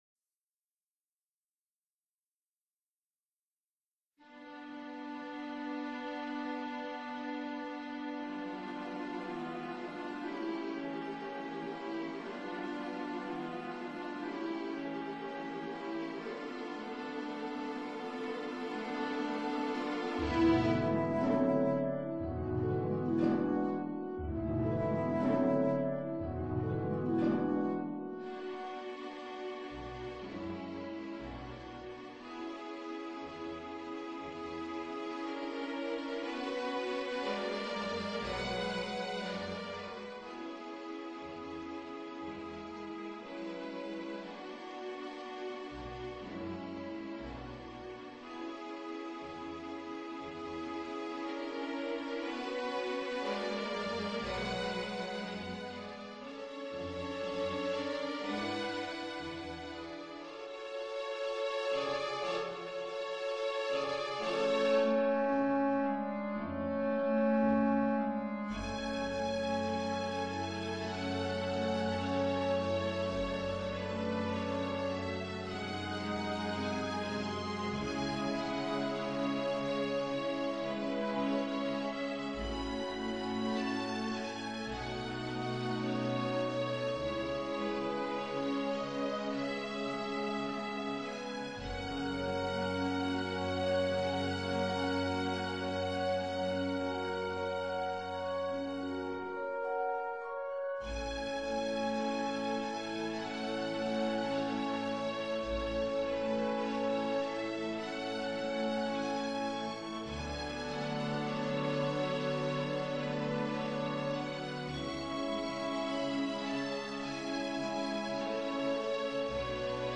Finale du Marchand de sable qui passe, op. 13, conte lyrique en un acte et en vers de Jean Aubry, musique de scène, Paris, E. Demets, 1910.